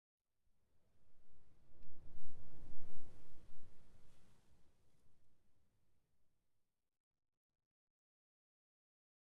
Minecraft Version Minecraft Version snapshot Latest Release | Latest Snapshot snapshot / assets / minecraft / sounds / block / dry_grass / wind8.ogg Compare With Compare With Latest Release | Latest Snapshot
wind8.ogg